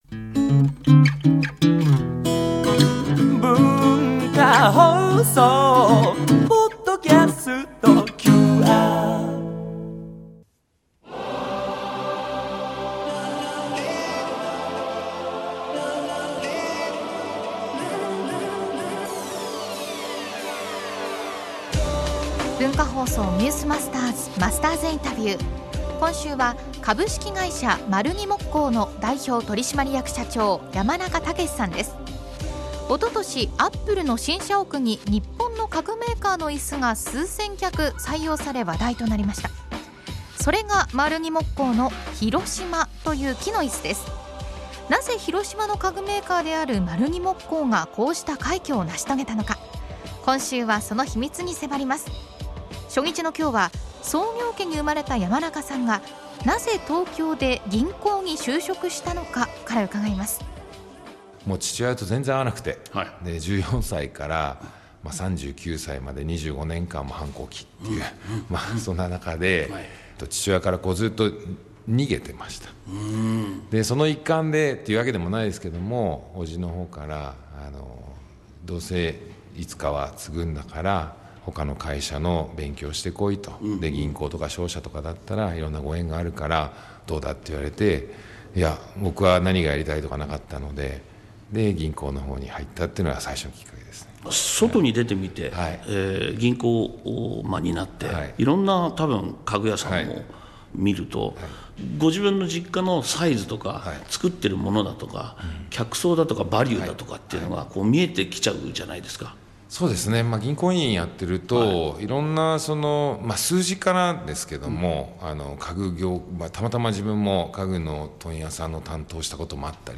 （月）～（金）AM7：00～9：00　文化放送にて生放送！